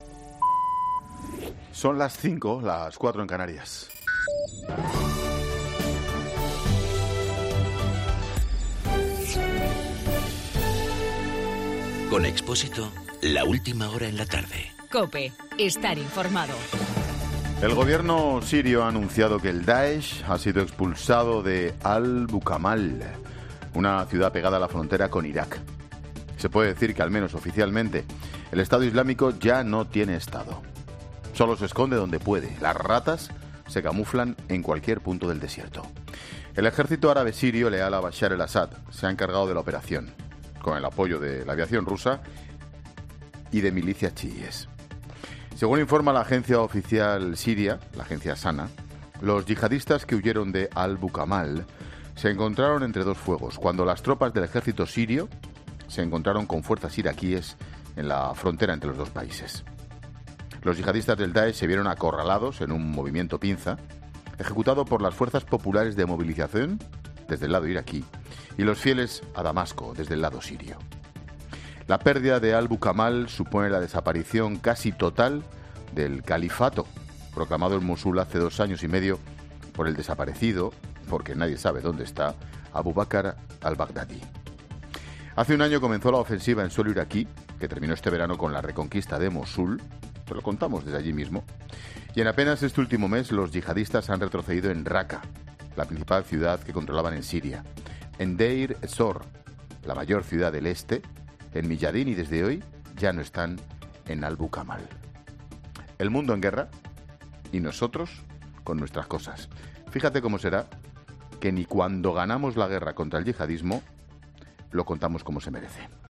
AUDIO: Ángel Expósito analiza en su monólogo de las 17 horas el fin de Estado Islámico en Siria.